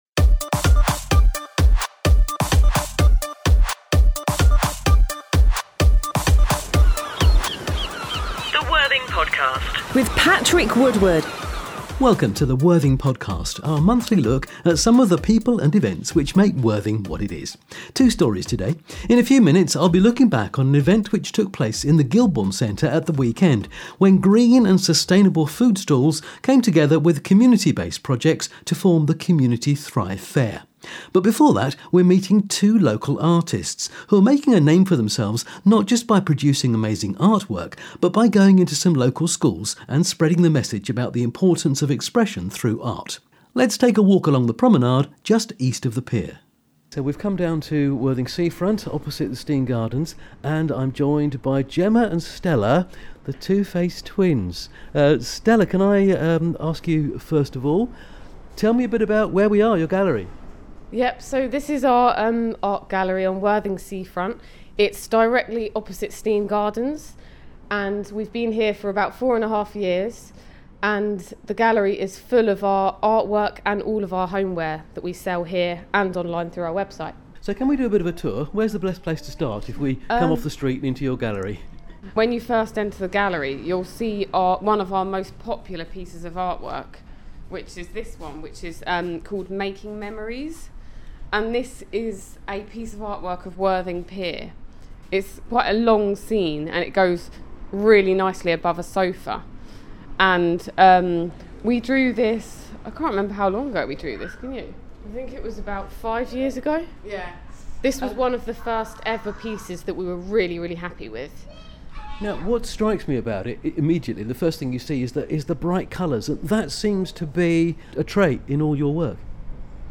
And he visits the Community Thrive Fair, bringing together community and sustainability causes in Worthing.